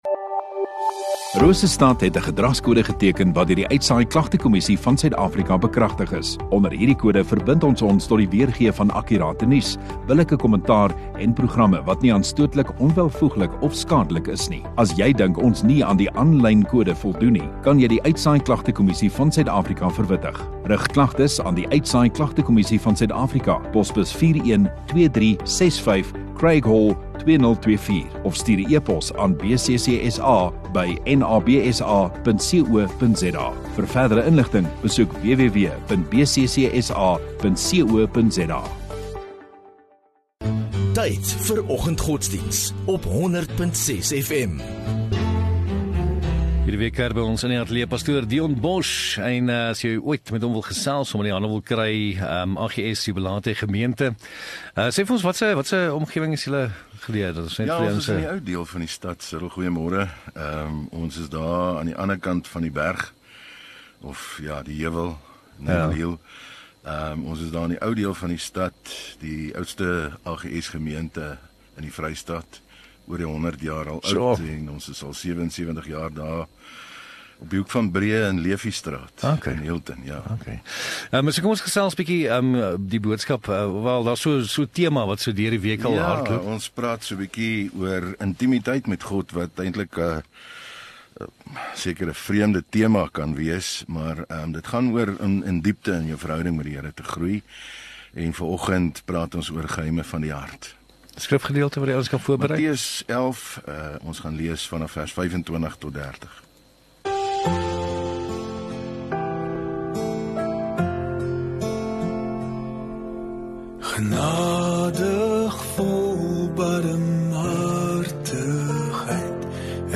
27 Jun Donderdag Oggenddiens